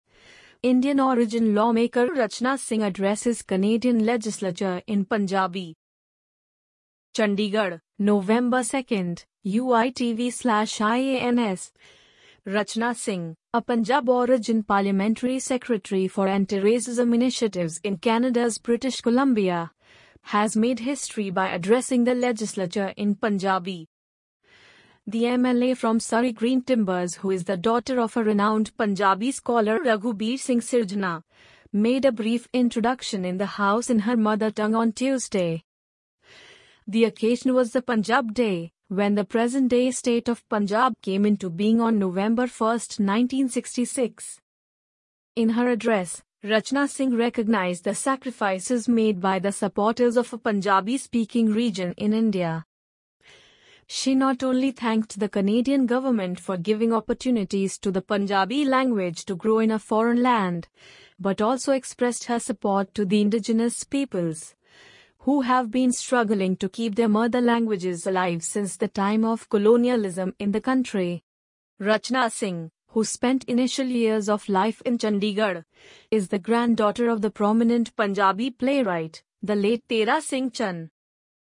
amazon_polly_14304.mp3